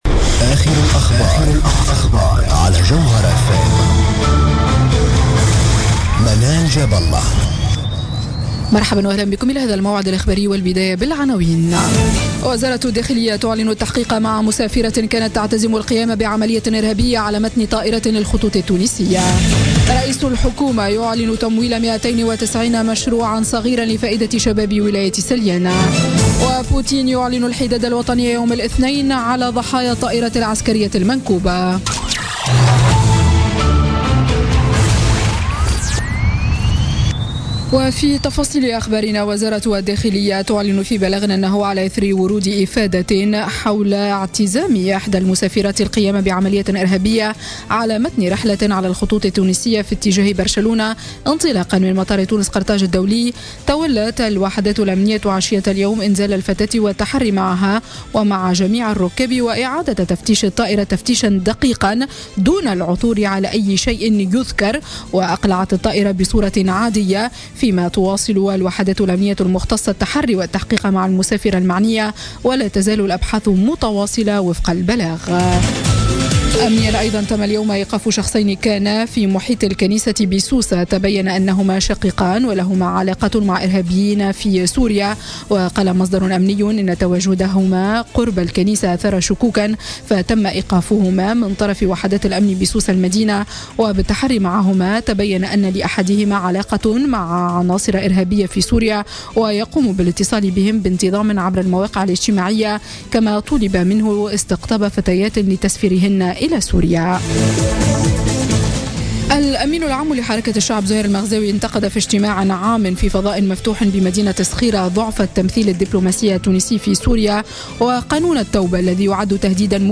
Journal Info 19h00 du dimanche 25 décembre 2016